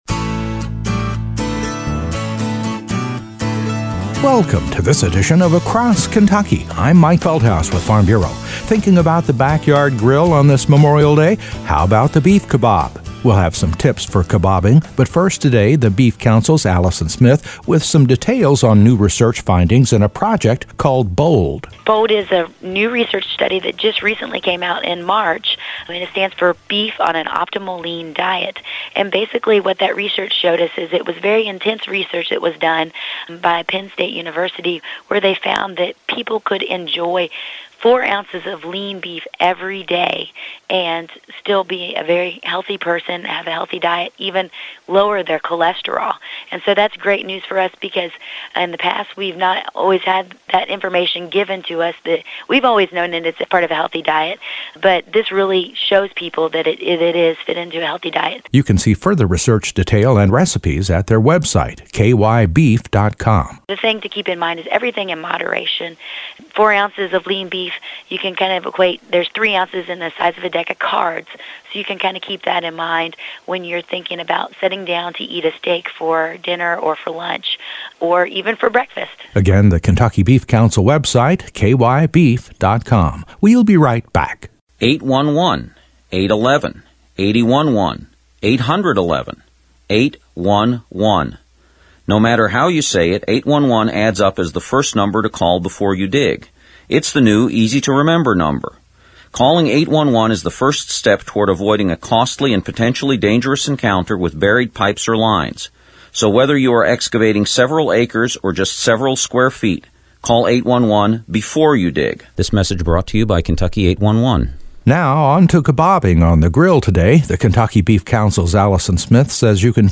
The second of two feature reports on the kick-off to summer grilling season.